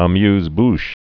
(ə-myzbsh)